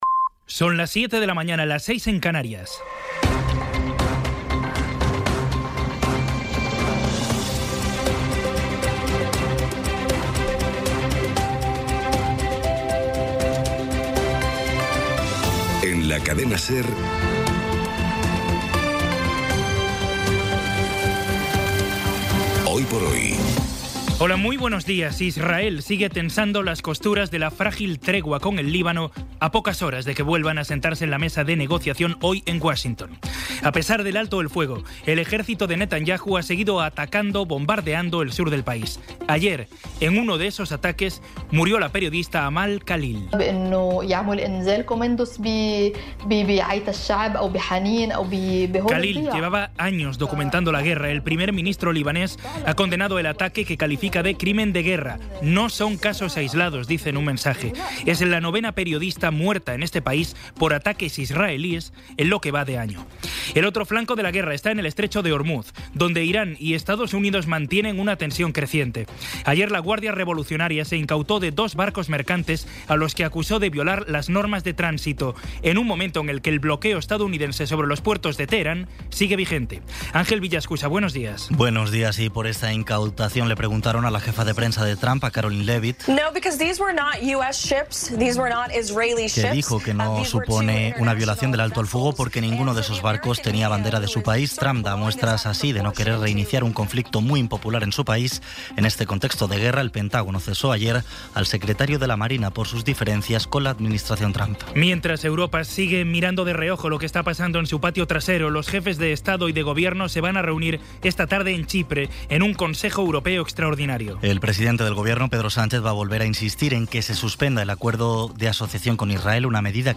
Resumen informativo con las noticias más destacadas del 23 de abril de 2026 a las siete de la mañana.